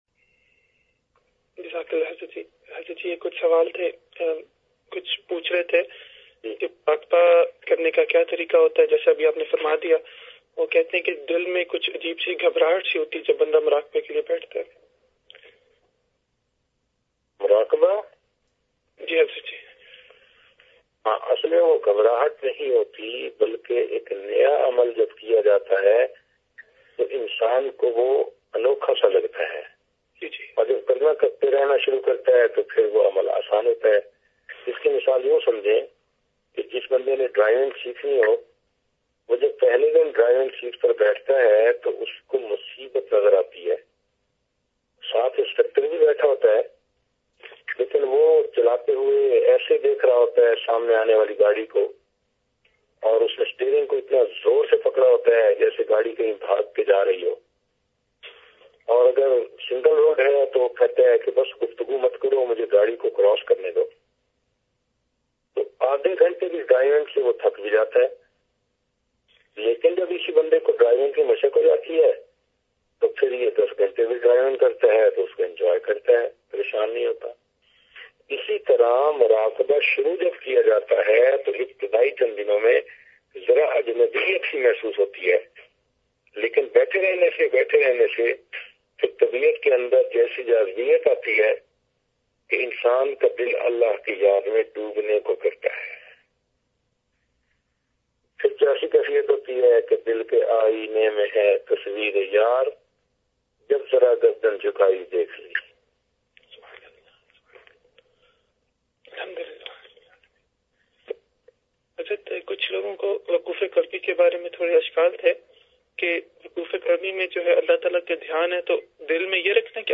questions_answers.mp3